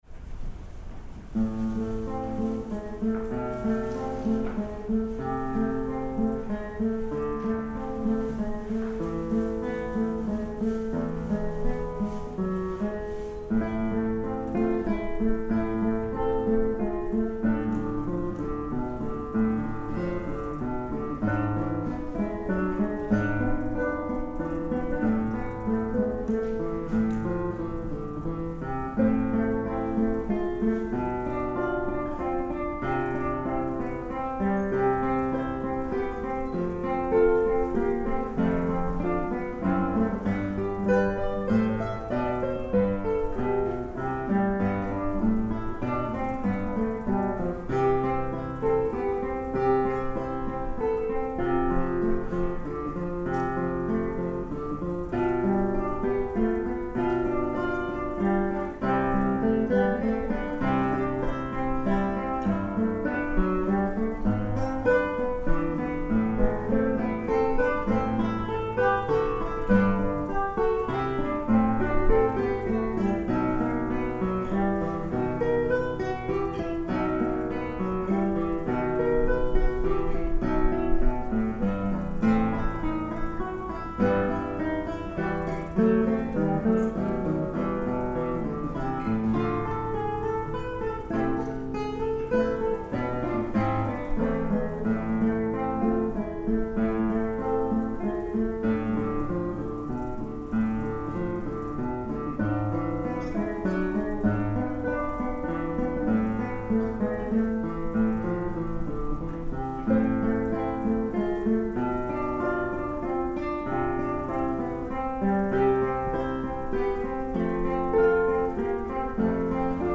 ギターコンサート
duo